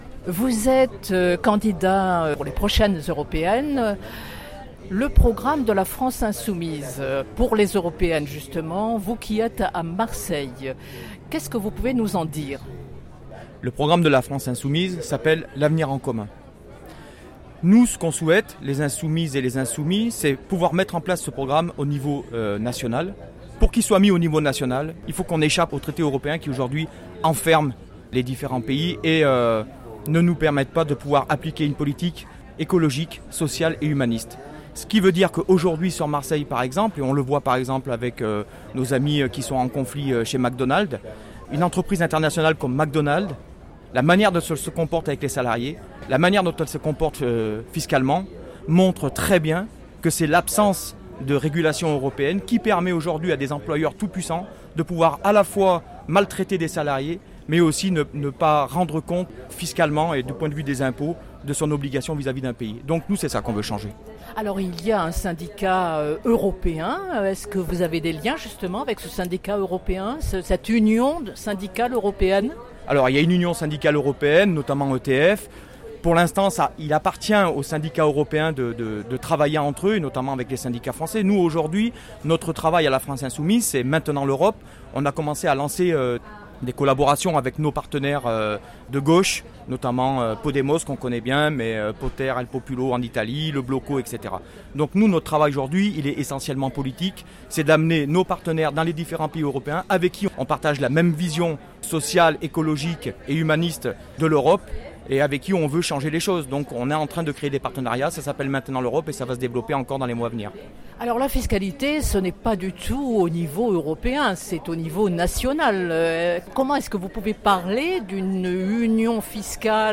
Les entretiens